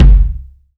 KICK.2.NEPT.wav